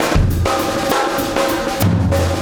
Extra Terrestrial Beat 23.wav